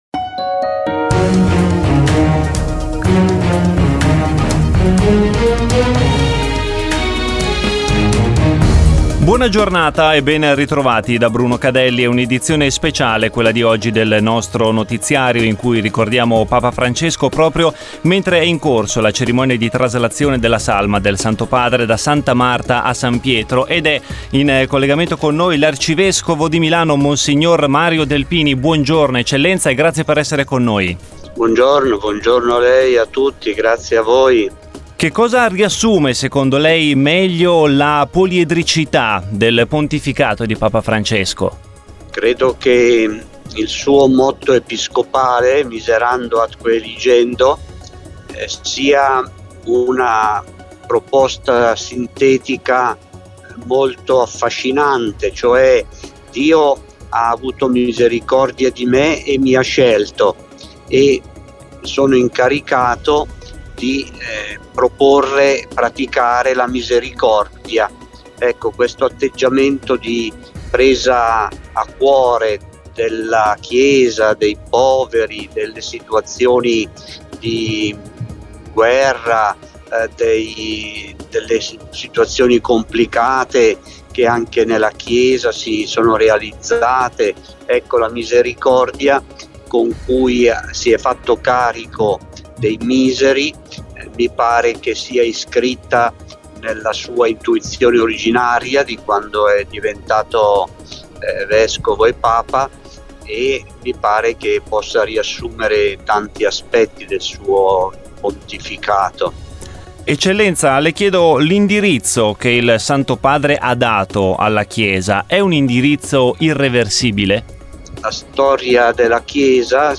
Insieme all’arcivescovo di Milano, mons. Mario Delpini, abbiamo ricordato il pontificato di Papa Francesco riflettendo su come custodire la sua eredità all’interno della Diocesi di Milano e sull’indirizzo dato alla Chiesa